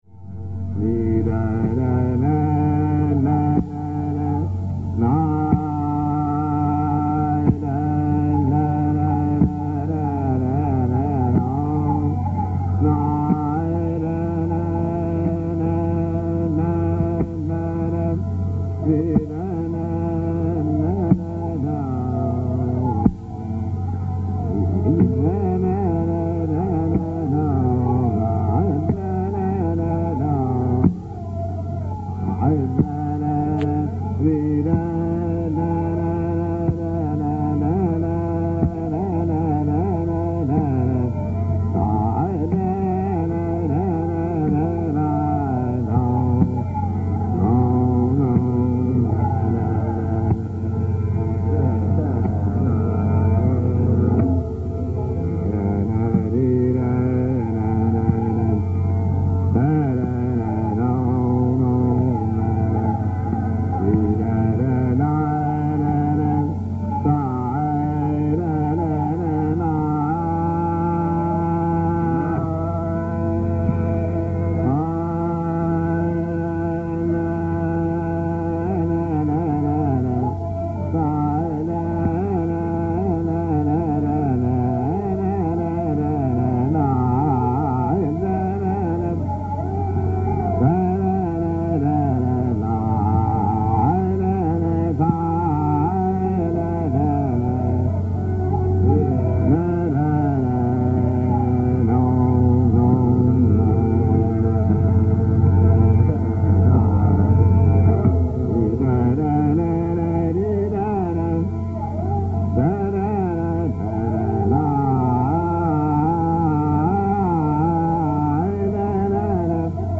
Raga Samant Sarang
Faiyyaz Khan‘s alap in Samant Sarang.